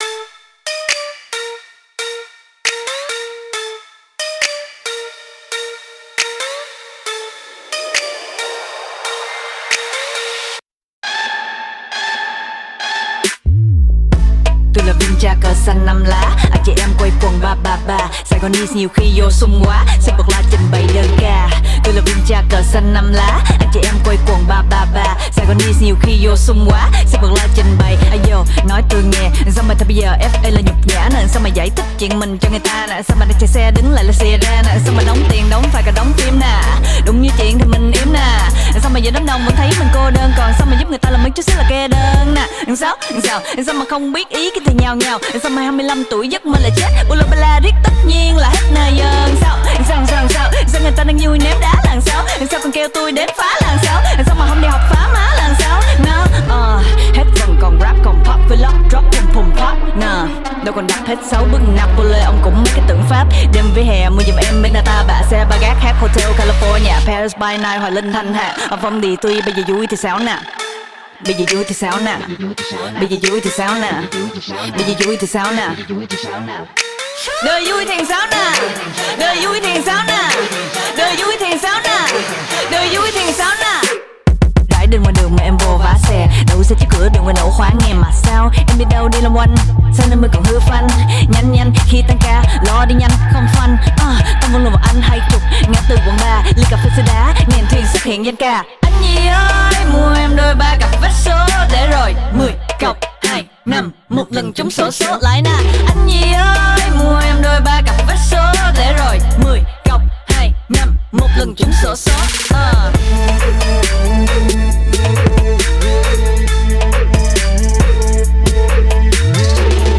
BPM136
MP3 QualityMusic Cut
Now rapping to a slightly faster beat.